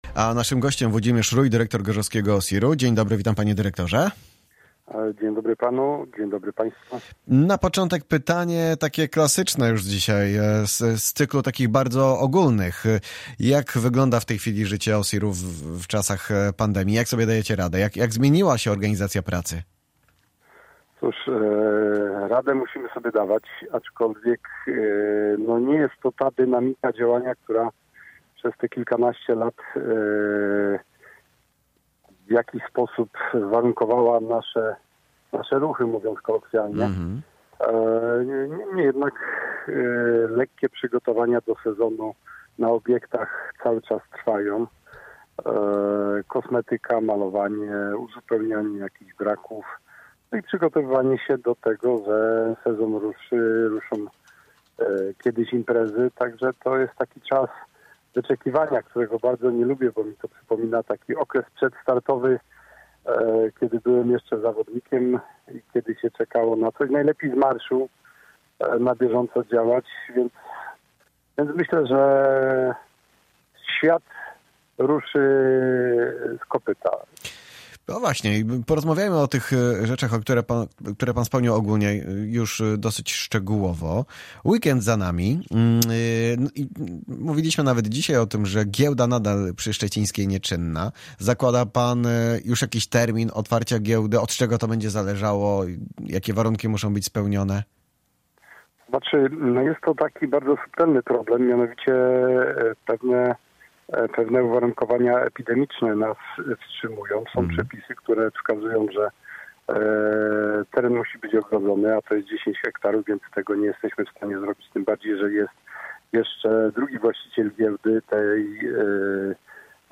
Audycje Gość na 95,6FM